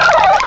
cry_not_shellos.aif